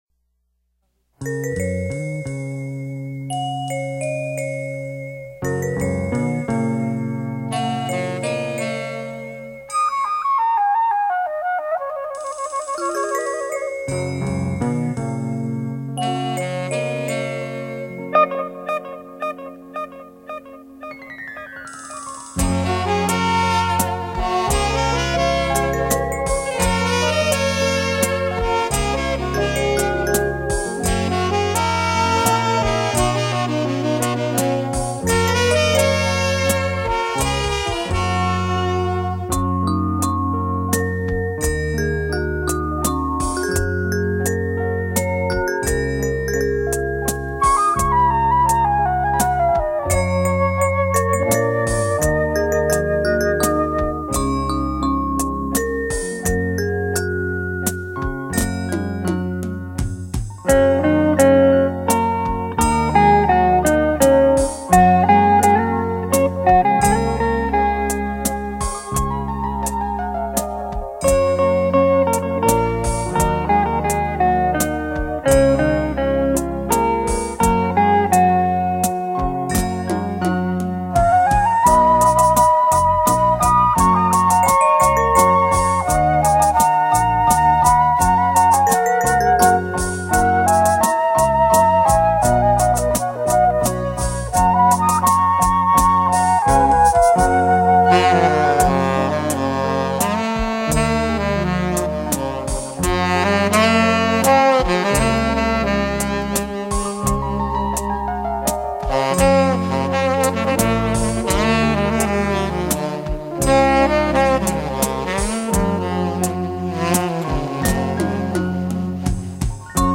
音乐类型： 轻音乐　　　　　　　　　.